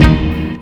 DreChron Orch Hit2.wav